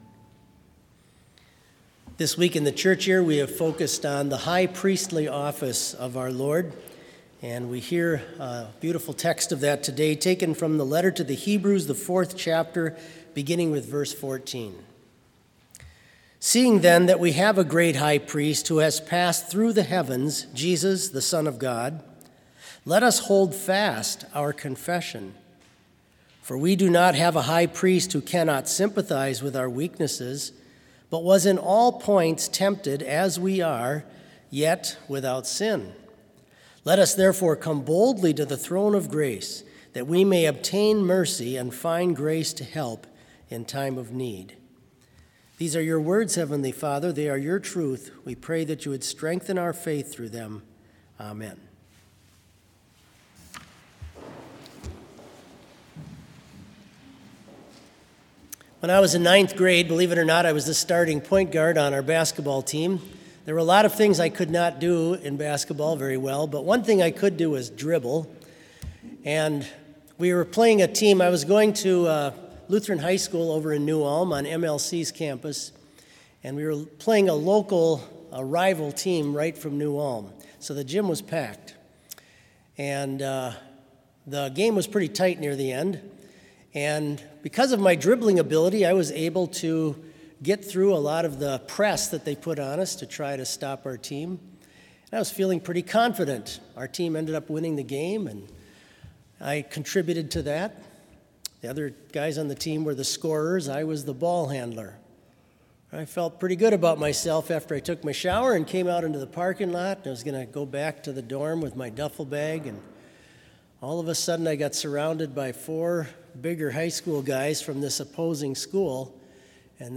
Complete service audio for Chapel - March 26, 2021
Sermon Only